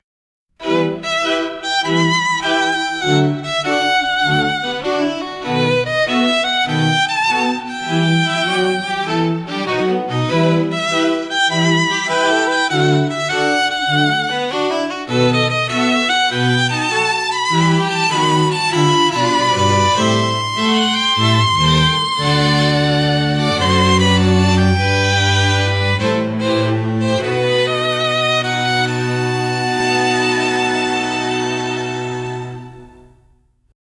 物語がいつまでも続くように願いを込めて、最後はあえて解決しない和音で幕を閉じました。